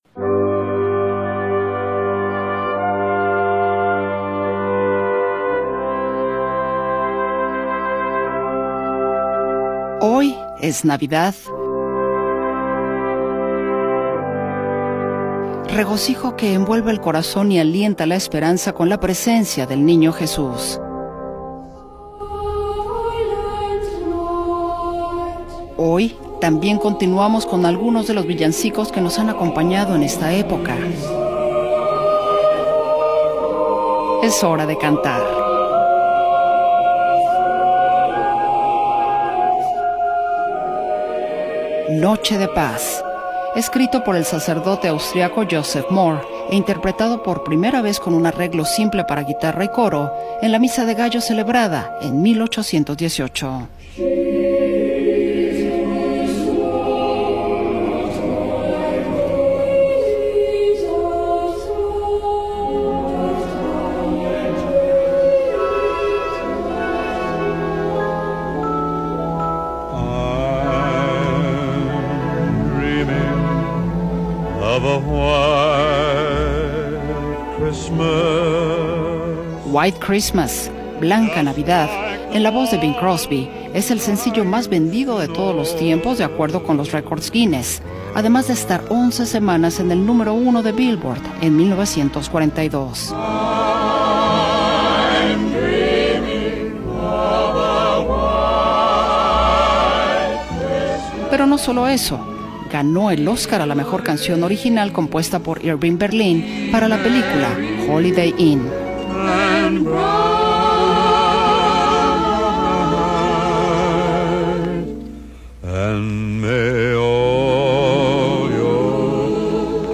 Se caracterizan por su tono alegre, melodías pegajosas y letras sencillas, que facilitan su interpretación en comunidad.
villancicos2.m4a